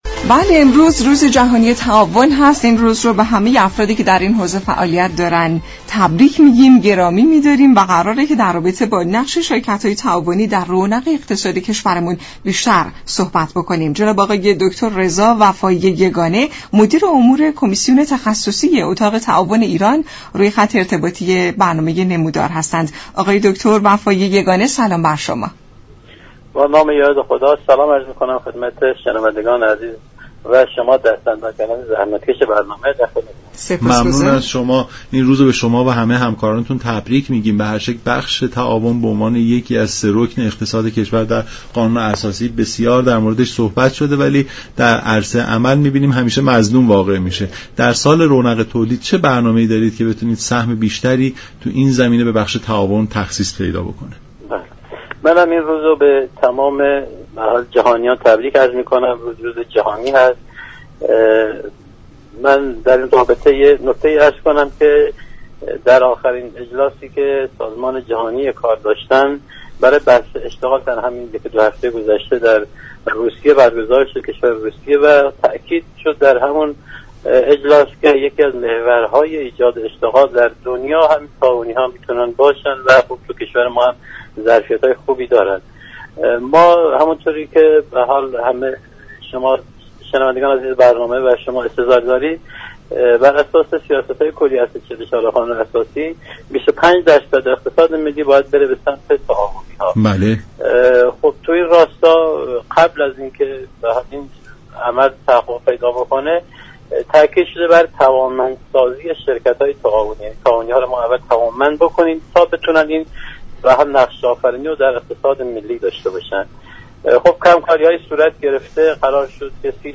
برنامه نمودار شنبه تا چهارشنبه هر هفته ساعت 10:20 از رادیو ایران پخش می شود. این گفت و گو را در ادامه می شنوید.